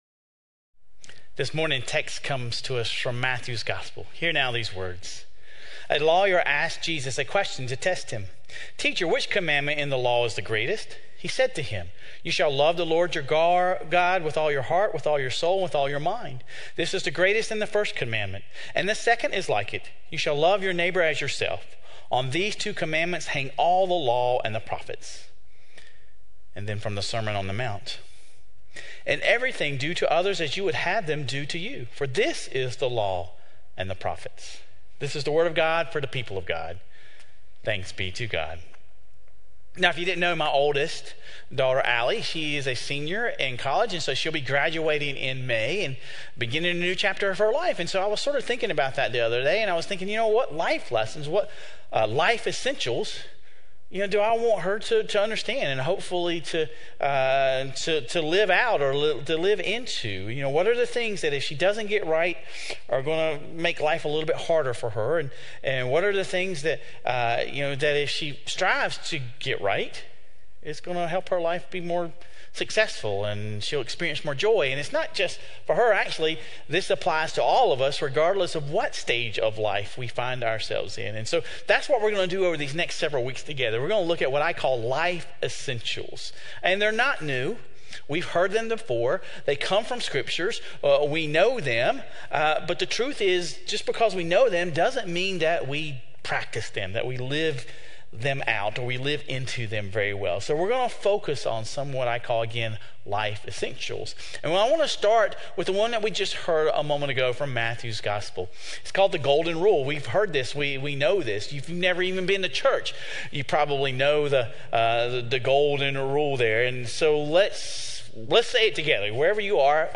This week, we explore the Golden Rule. Sermon Reflections: How does the sermon illustrate the universality of the Golden Rule across various religions and ethical systems?